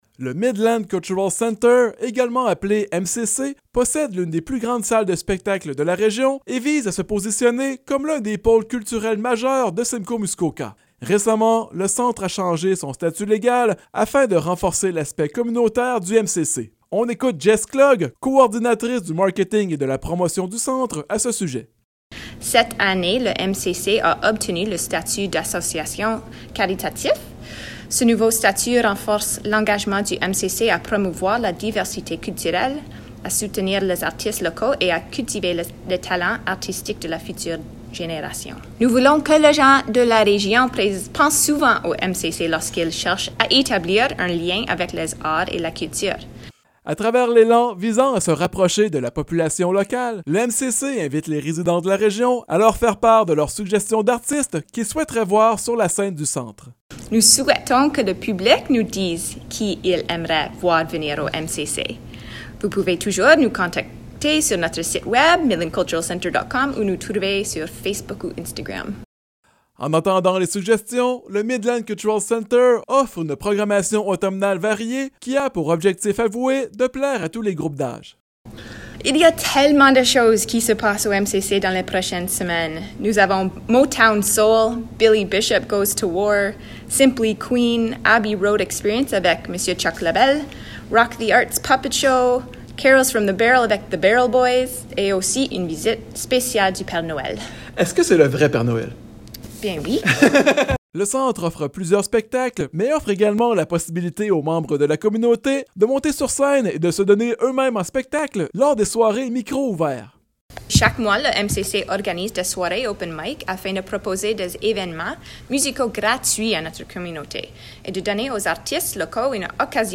Entrevue-Mcc.mp3